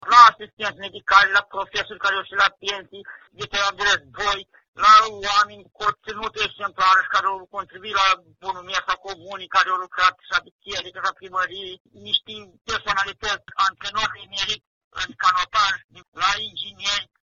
Primarul Ştefan Iordache, care a condus comuna 16 ani, continuă lista.
Clip-primar-lista-de-cetateni.mp3